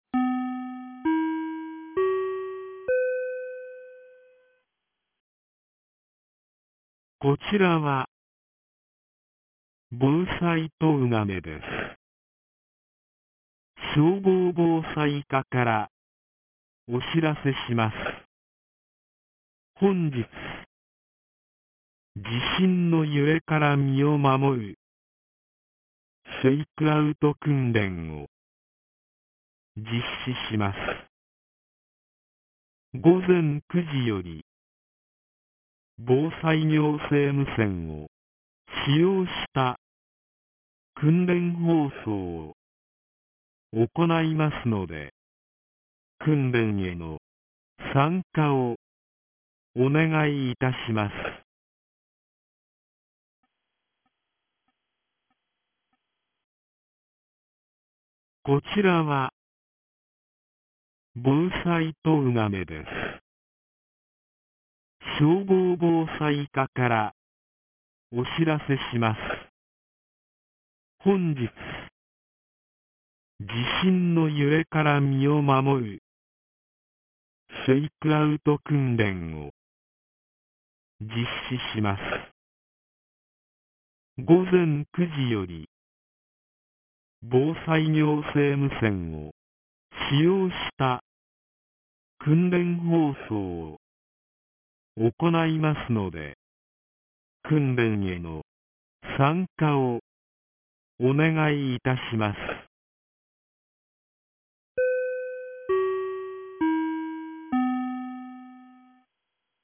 2025年09月03日 08時32分に、東金市より防災行政無線の放送を行いました。